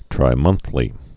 (trī-mŭnthlē)